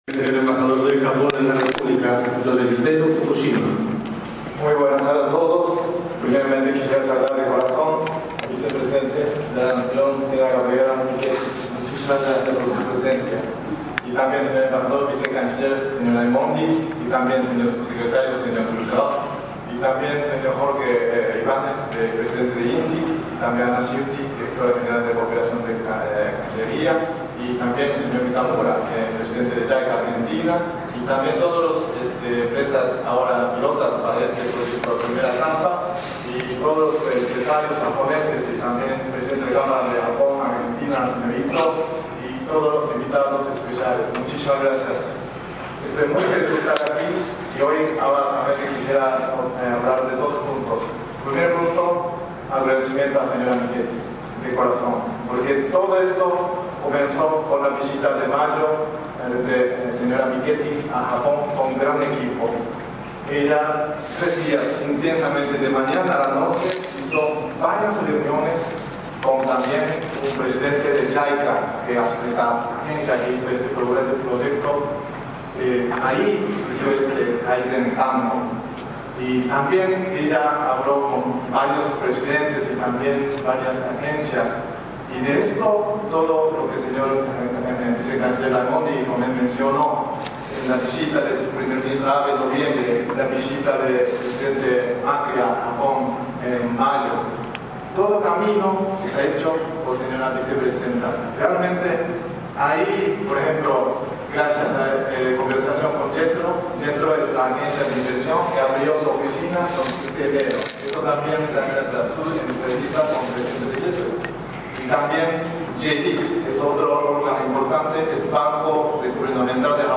En las bellísimas  instalaciones del Palacio San Martín conservadas y cuidadas como en sus orígenes  el miércoles 25  de octubre se lanzó oficialmente el proyecto “Red de Asistencia Técnica para Oportunidades Globales de Kaizen”, denominado  “Kaizen-Tango”
Audio: Embajador del Japón, Noriteru Fukushima